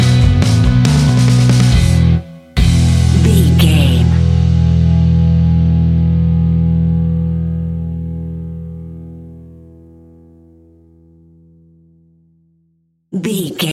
Ionian/Major
indie pop
fun
energetic
uplifting
cheesy
instrumentals
upbeat
rocking
groovy
guitars
bass
drums
piano
organ